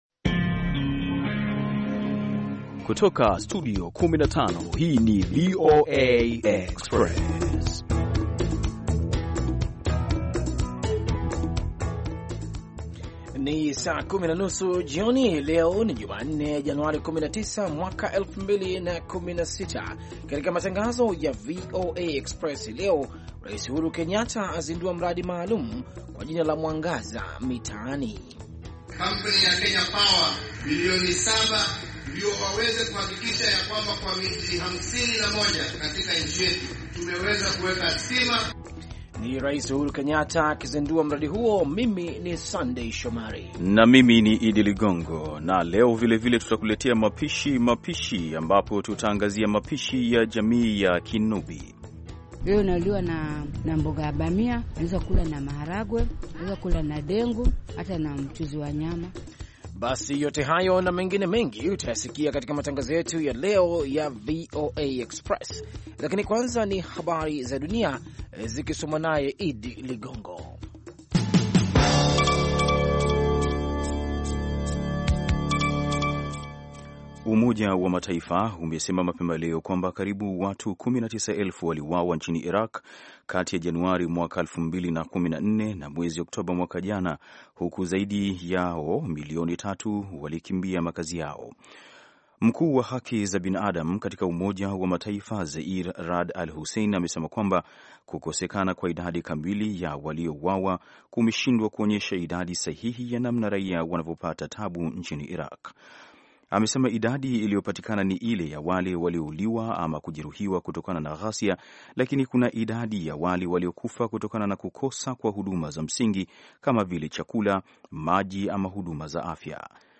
VOA Express ni matangazo mapya yenye mwendo wa kasi yakiangalia habari mpya za mchana na maelezo ya maswala yanayohusu vijana na wanawake. Matangazo haya yanafuatilia habari zilizojitokeza nyakati za mchana na ripoti za kina za habari ambazo hazisikiki sana katika matangazo mengineyo.